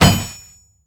safe_drop_01.ogg